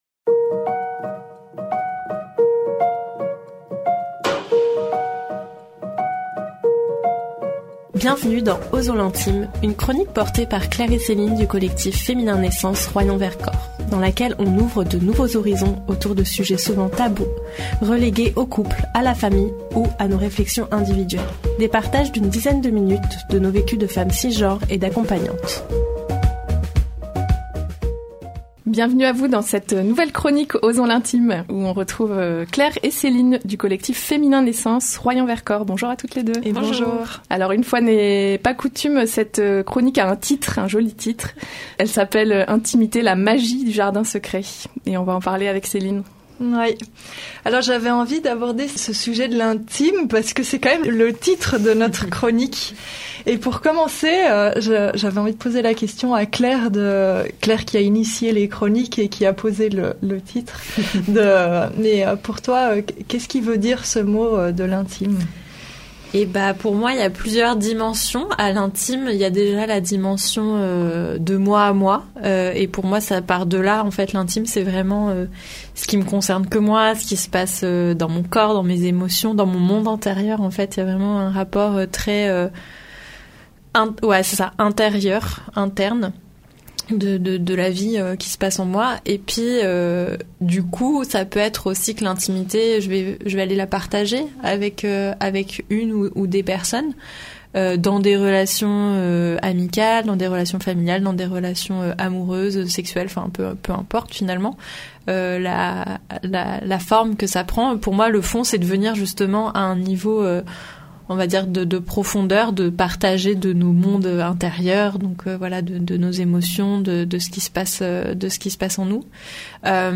Des partages d’une dizaine de minutes autour de leurs vécus de femmes cisgenres et d’accompagnantes.